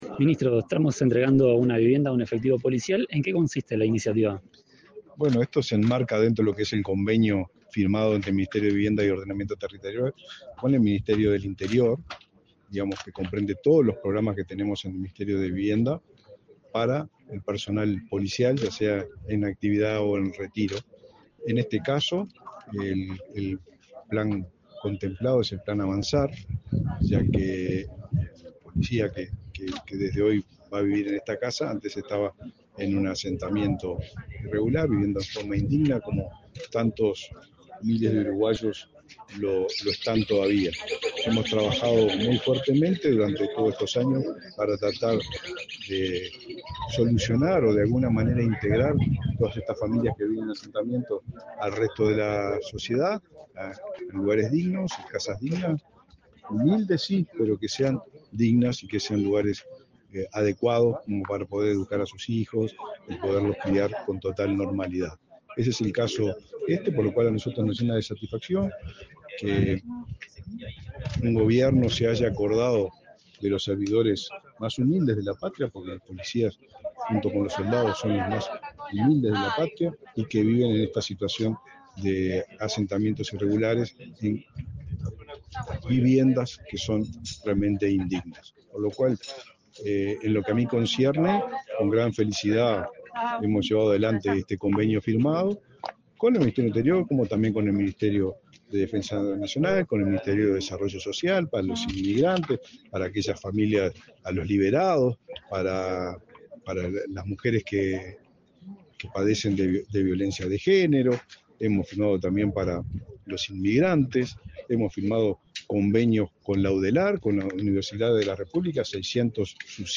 Declaraciones a la prensa del ministro de Vivienda y Ordenamiento Territorial, Raúl Lozano